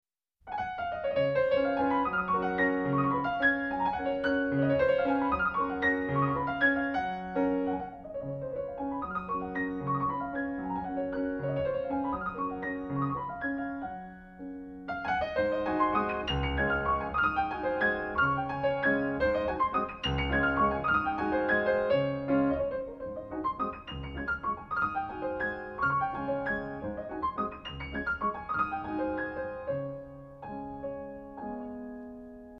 Pianist
refined, patrician touch